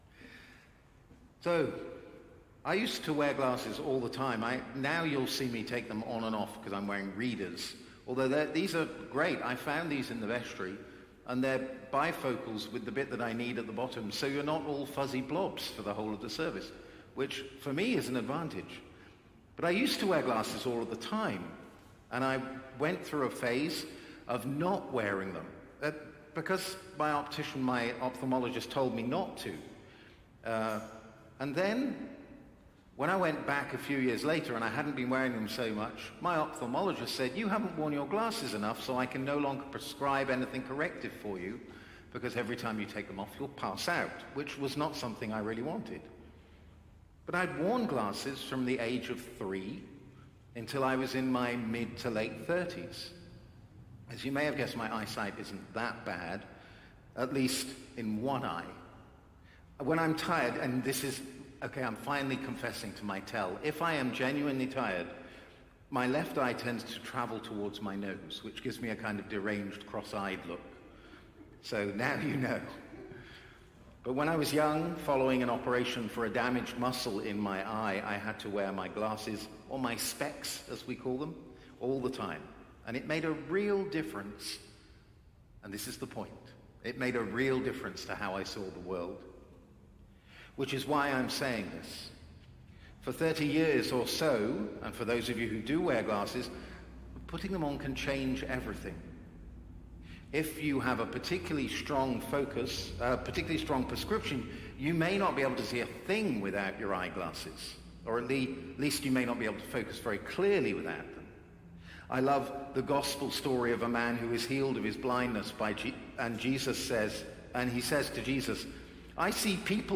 Sermons | St. John the Divine Anglican Church
Evensong Reflection